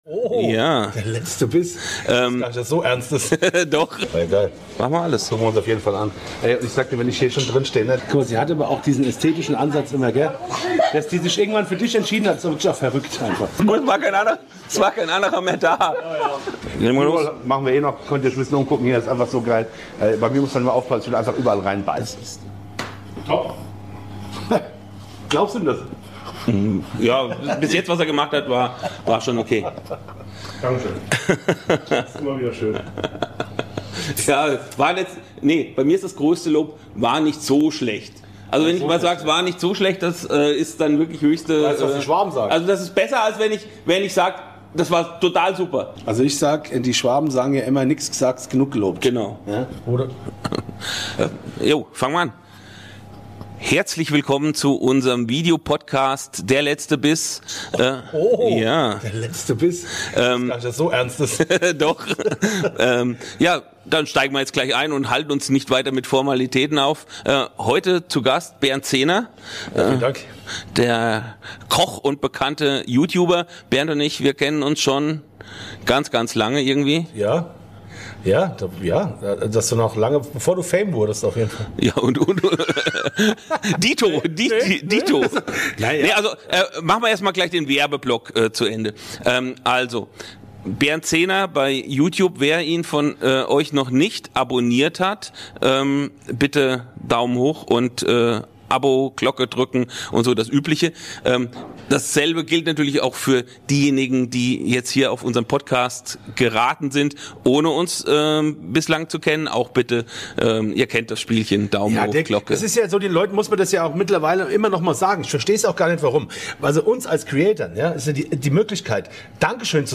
Zwei Stimmen aus der BBQ- und Food-Szene. Offen. Direkt. Ehrlich.